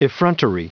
Prononciation du mot effrontery en anglais (fichier audio)
Prononciation du mot : effrontery